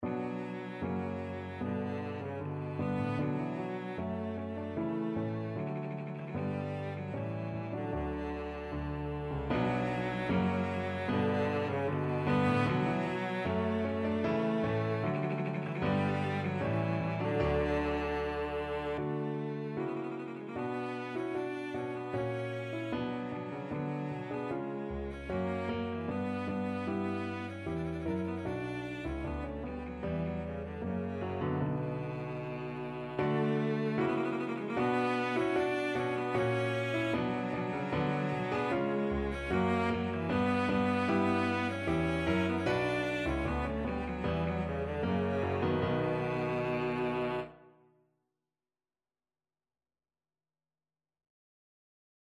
Cello
B minor (Sounding Pitch) (View more B minor Music for Cello )
Steadily =c.76
Classical (View more Classical Cello Music)